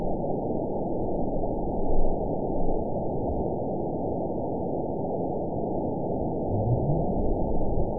event 912351 date 03/25/22 time 06:18:49 GMT (3 years, 1 month ago) score 9.61 location TSS-AB01 detected by nrw target species NRW annotations +NRW Spectrogram: Frequency (kHz) vs. Time (s) audio not available .wav